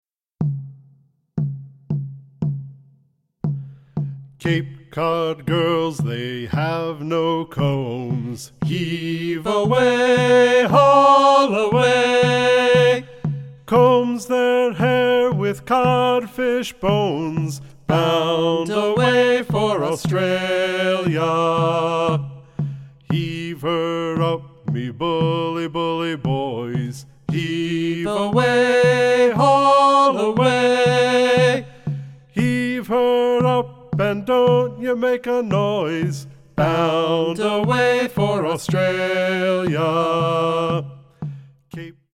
Celtic music